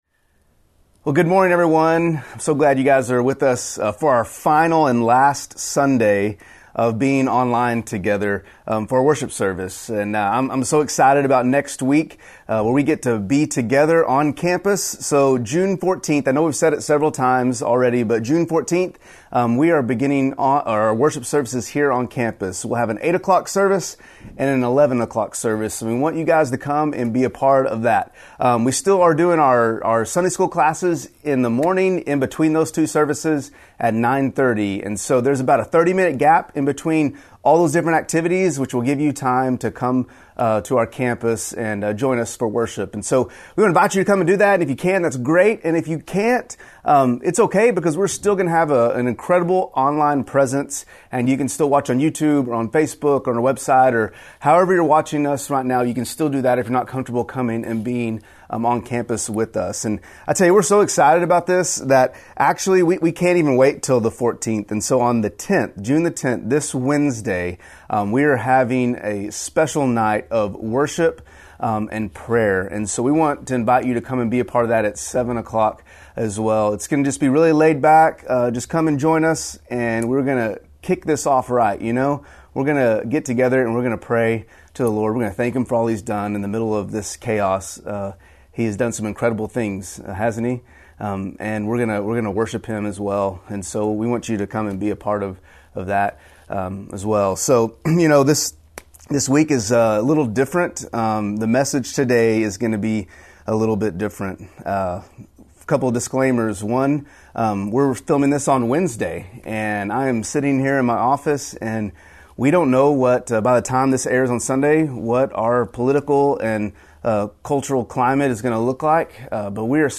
A message from the series "Best Sermon Ever."